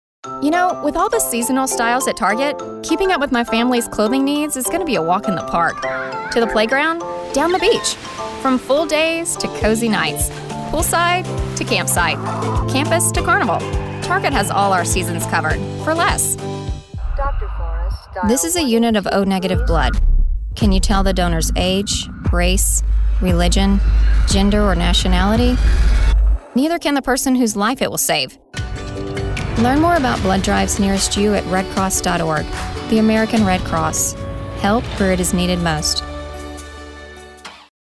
Voice actor sample
진취/힘있는